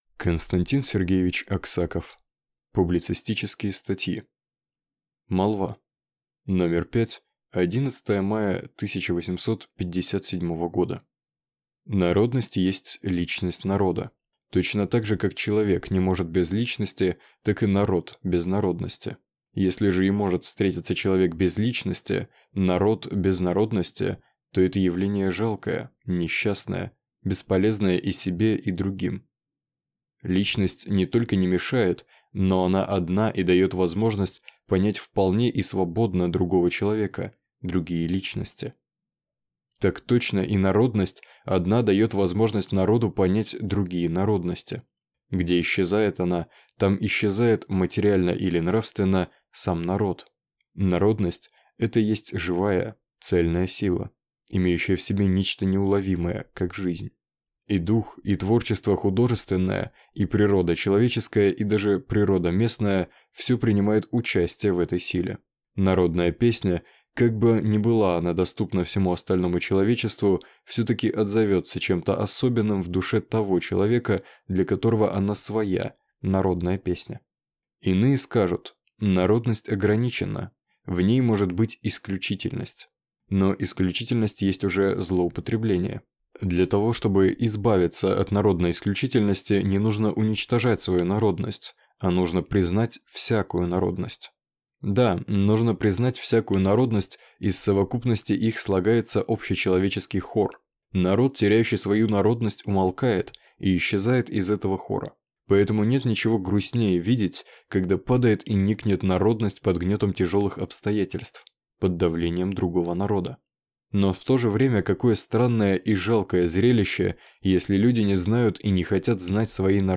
Аудиокнига Публицистические статьи | Библиотека аудиокниг